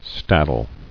[stad·dle]